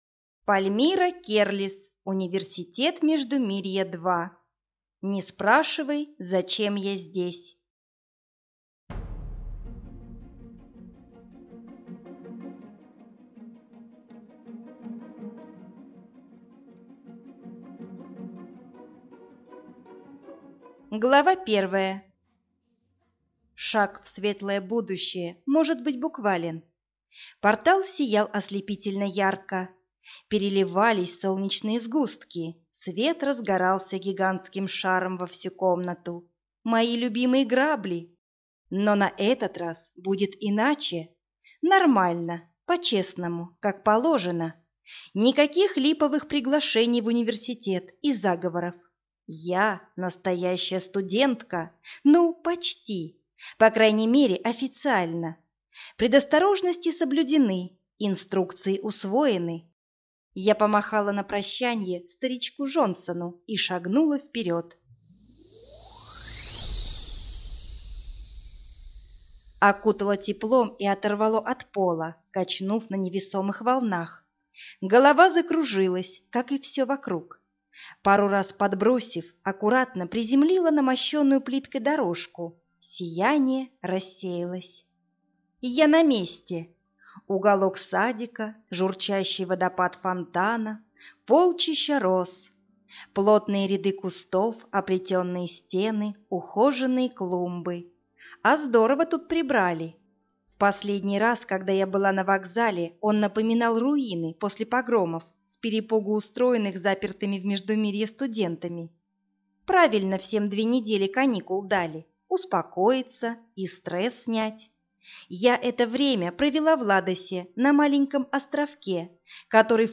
Аудиокнига Университет Междумирья. Не спрашивай, зачем я здесь | Библиотека аудиокниг